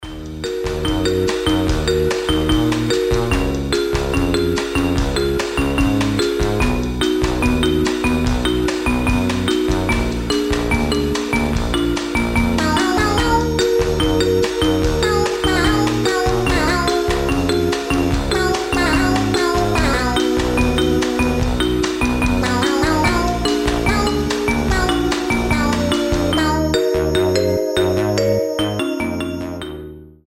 мелодичные , забавные
спокойные , без слов